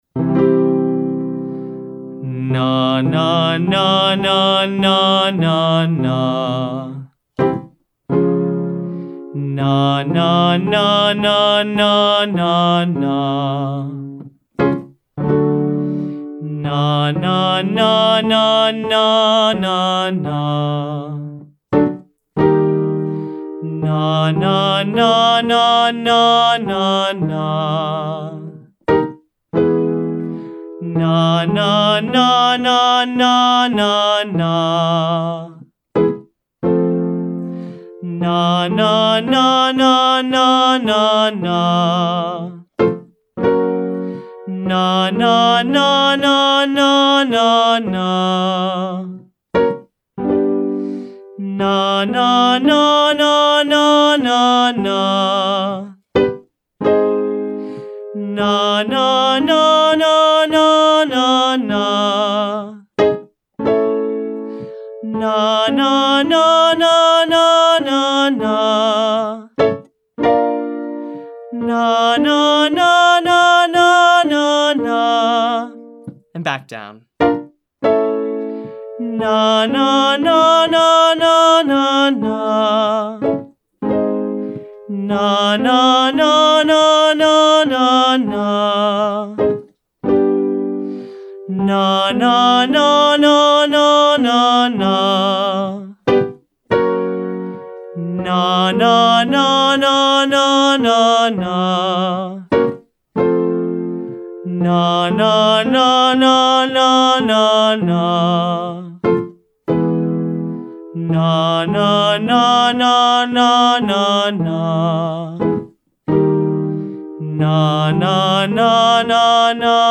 Exercise 2: Musical Theater Tone (Nah 1,3,555,3,1)
Singing musical theater also requires lots of support, but more of a forward or mixed placement for resonance for belting, and a combination of straight tone and vibrato.